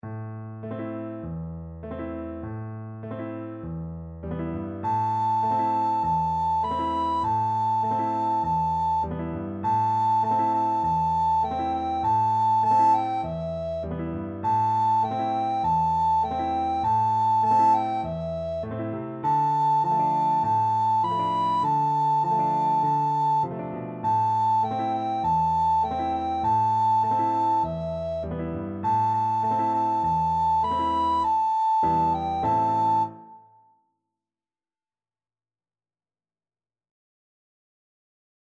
Traditional Trad. Kagome Kagome Soprano (Descant) Recorder version
A minor (Sounding Pitch) (View more A minor Music for Recorder )
Moderato
4/4 (View more 4/4 Music)
Traditional (View more Traditional Recorder Music)